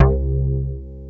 ANALBAS4C2-R.wav